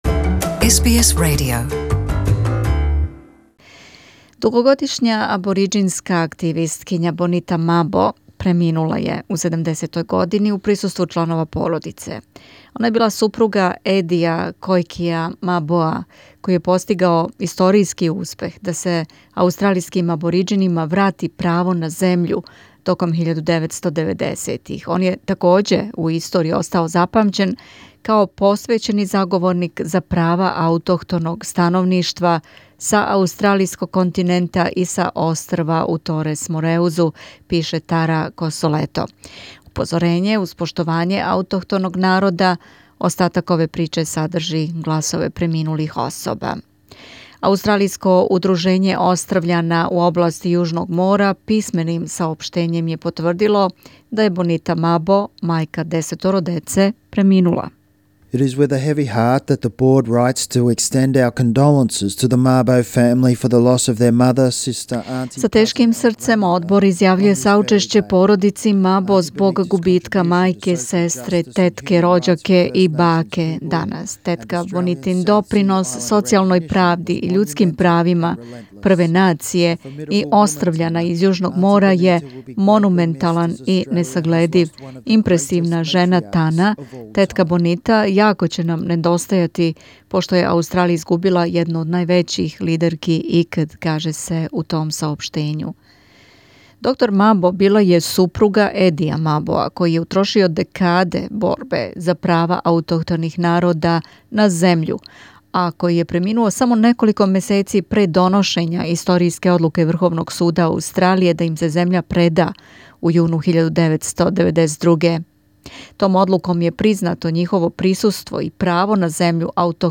Упозорење, уз поштовање аутохтоног народа, ова прича садржи гласове преминулих особа